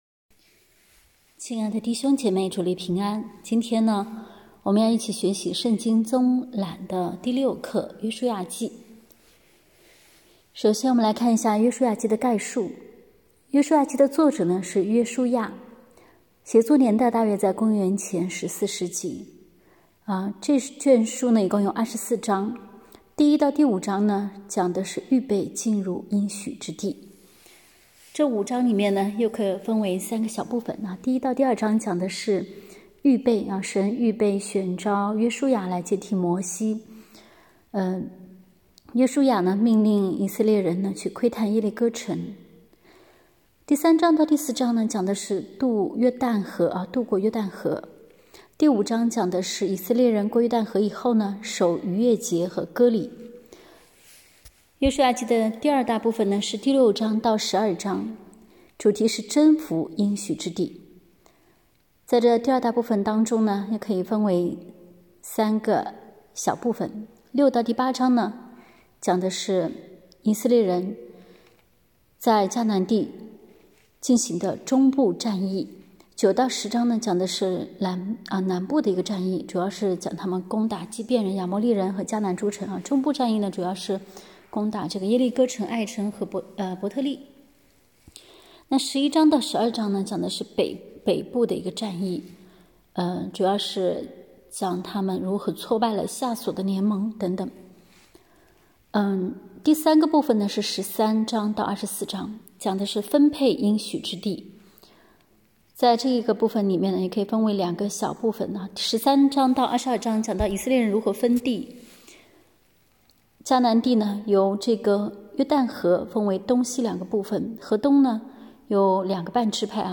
课程音频